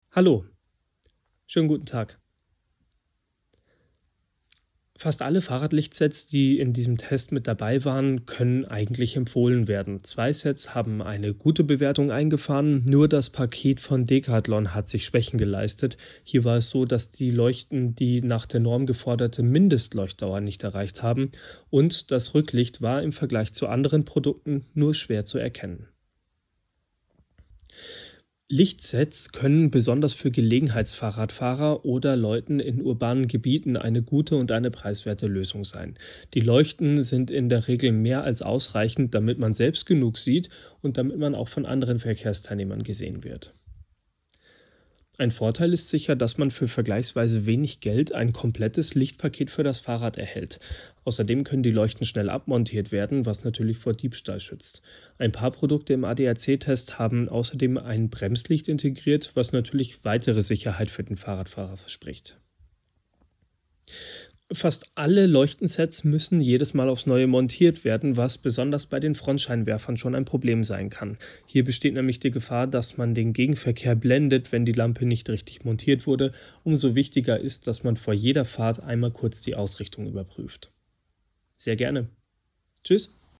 o-toene_adac_fahradlicht-set-test_2025.mp3